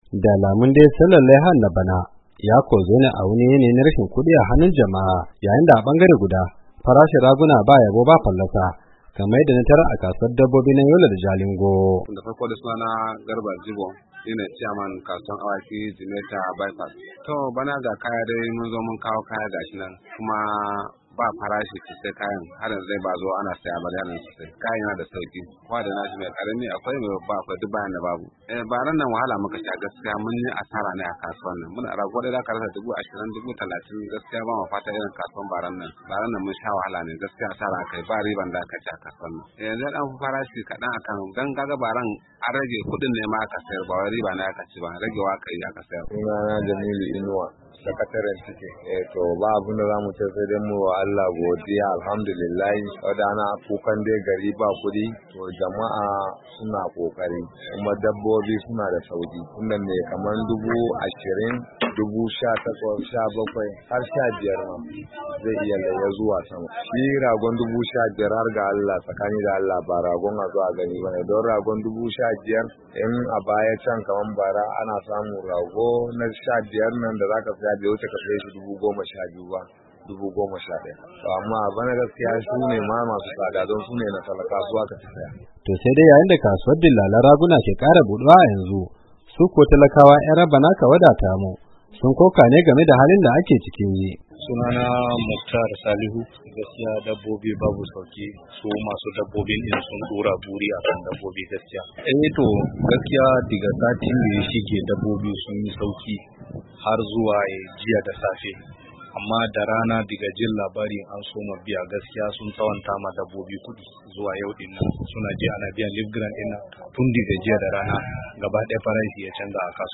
OYO: Kasuwar Raguna a Ibadan